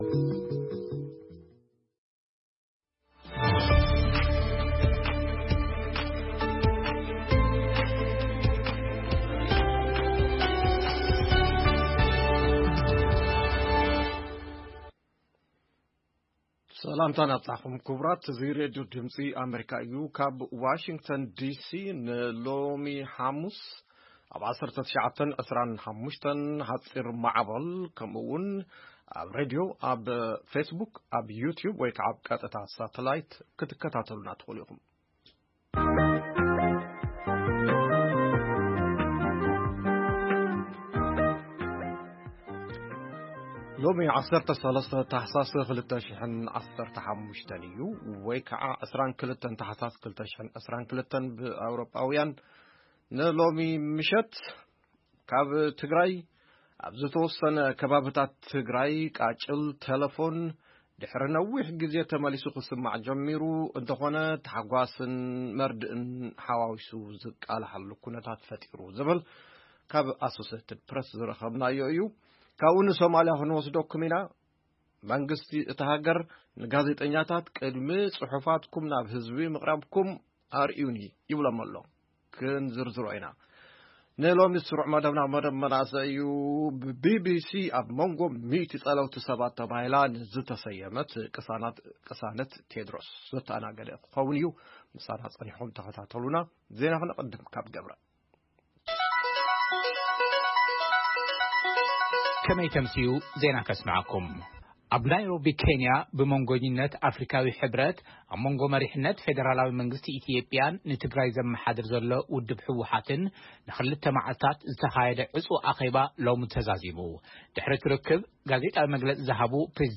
ቃለ መሕትት የጠቓልል።